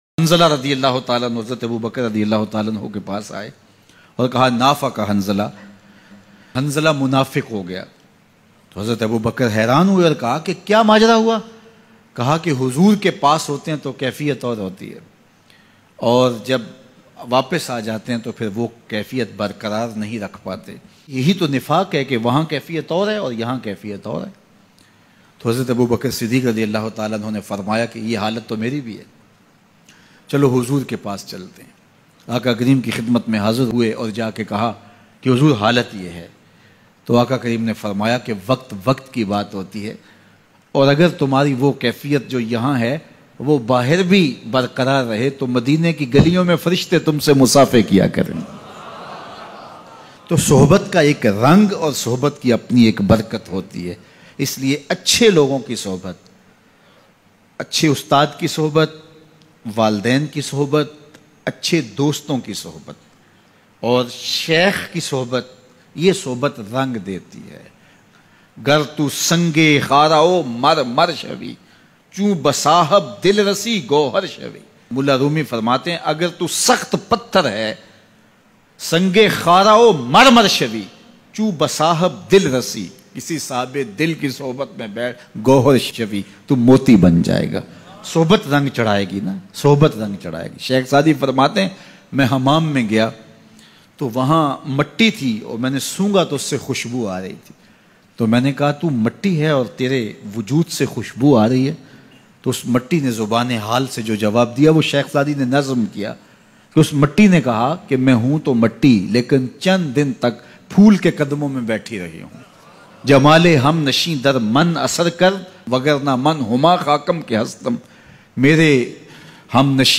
HUZOOR hum munafiq ho gye Emotional Bayan